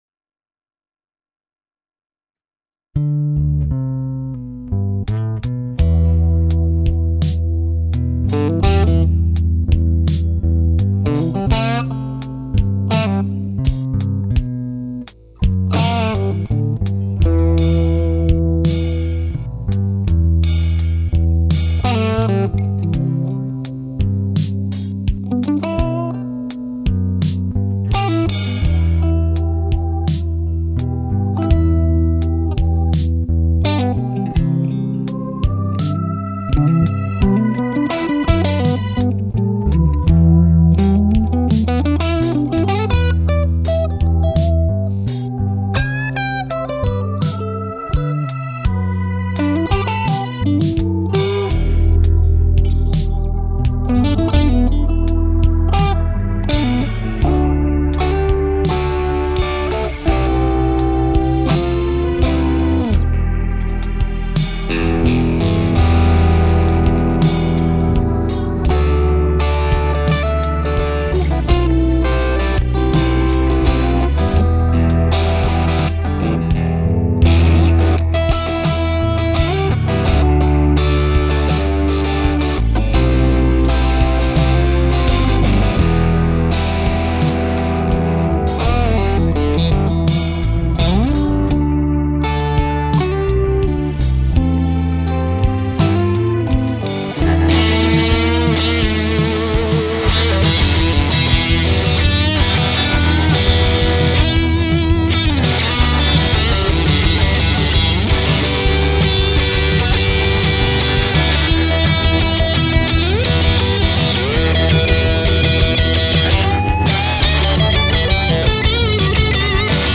Ist alles Strat und PODXT.